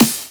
Snare 9 (Evil As Satan).wav